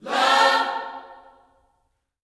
LOVECHORD4.wav